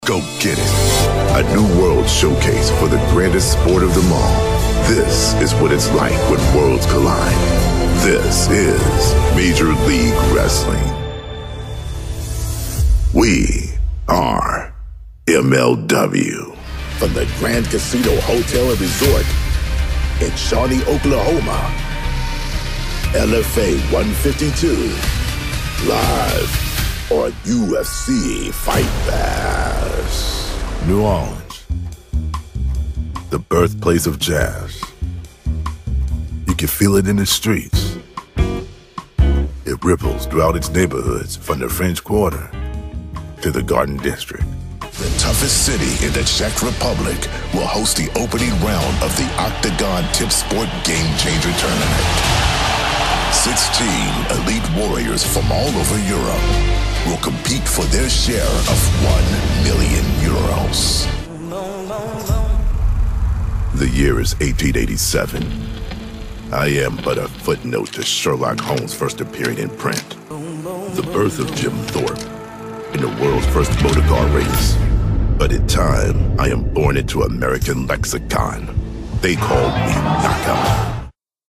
Female Voice Over, Dan Wachs Talent Agency.
Epic, Iconic, Motivational.
Promo